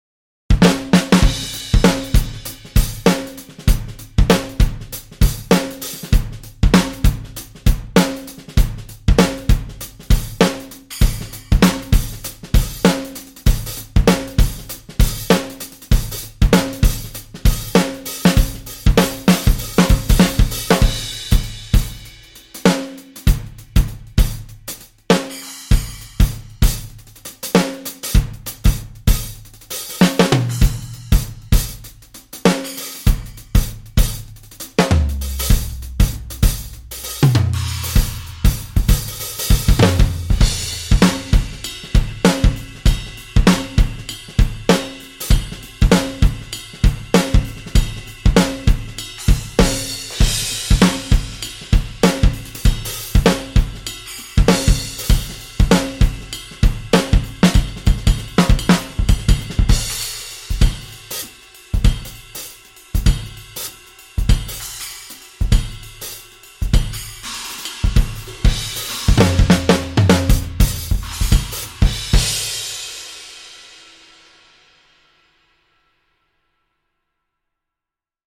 六 个原声鼓套件和超过 400 种经典鼓机声音
在英国伦敦的英国格罗夫工作室拍摄
为了增加更加有机和正确的时期风味，所有鼓和机器在数字转换之前都被记录到磁带上。
热门制作人 SDX -讲故事的人套件